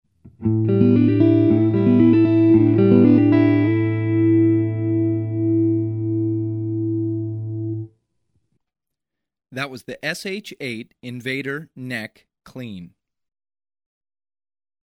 • Biedt als contrast een warmer en iets meer beteugeld basissound voor in de halspositie.
Seymour Duncan SH-8n Invader Halselement: clean sound Audio Onbekend
sh-8n_neck_clean.mp3